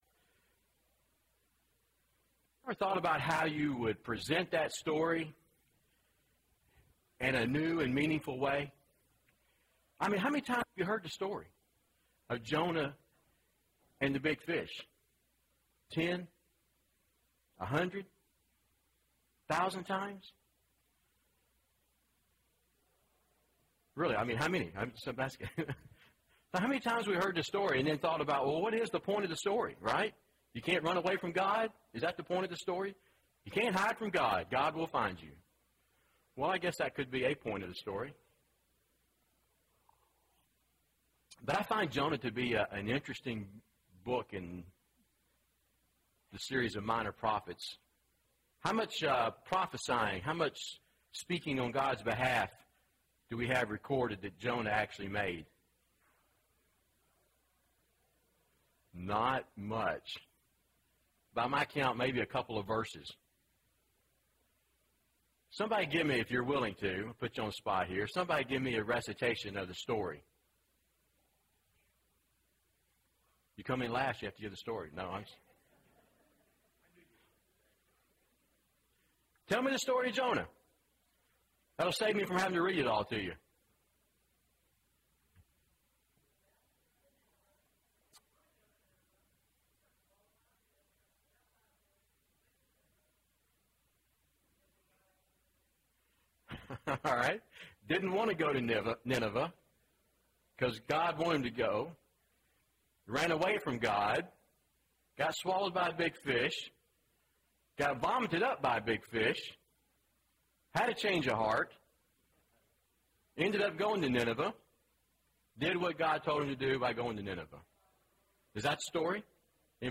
Will God Save Just Anyone? (8 of 12) – Bible Lesson Recording